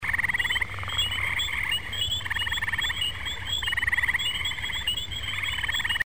Gray Treefrog